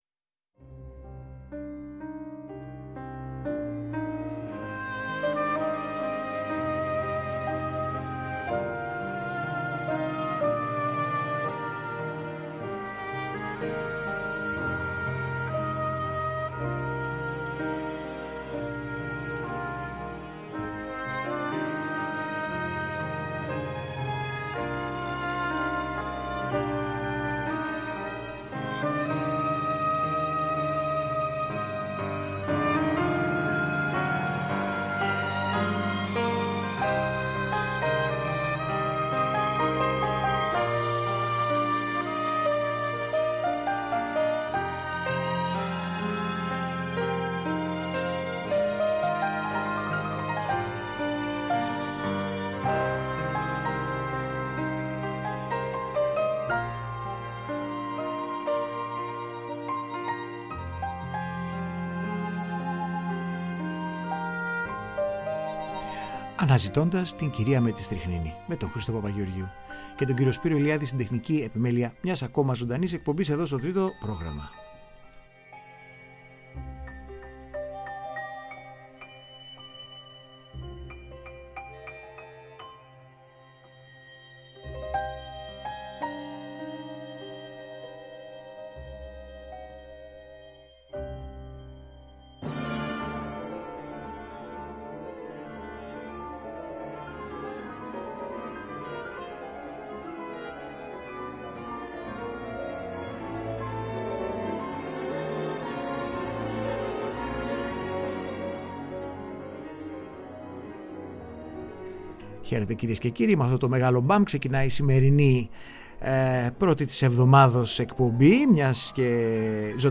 ζωντανά στο Τρίτο πρόγραμμα μιλάει και παίζει